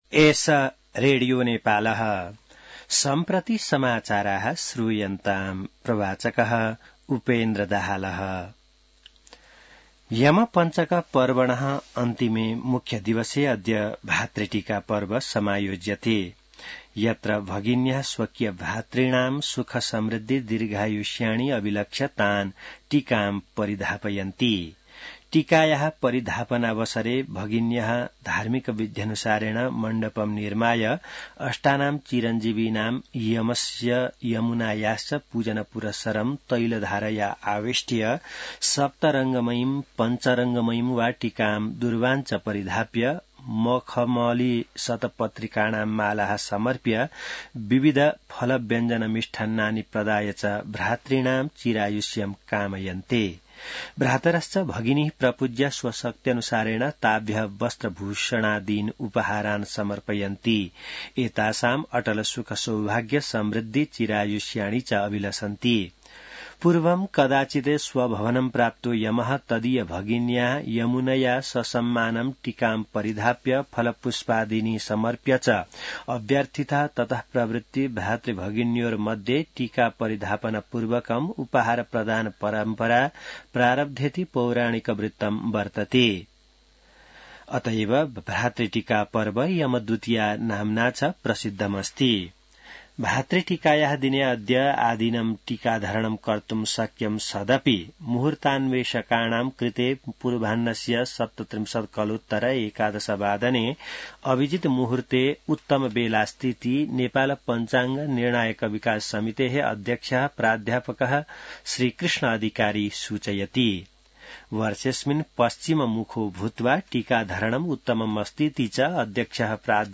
संस्कृत समाचार : १९ कार्तिक , २०८१